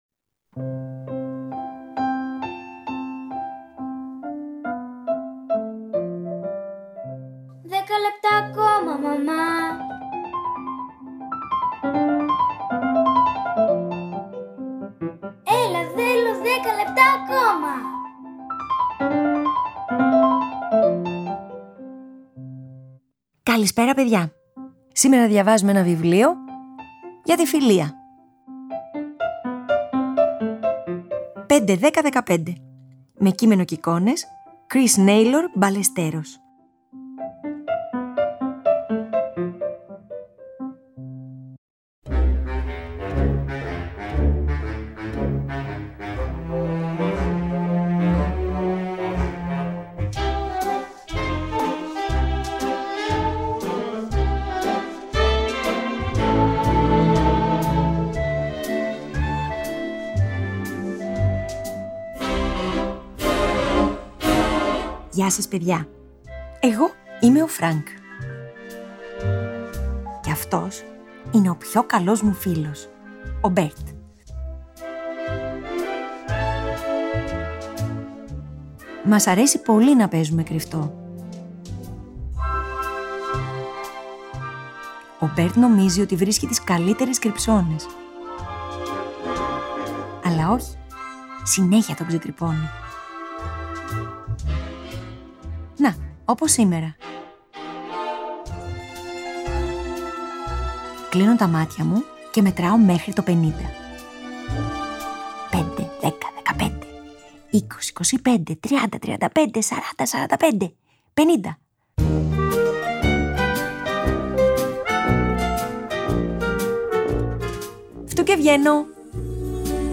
Μια τρυφερή παιδική ιστορία για τη δύναμη της φιλίας.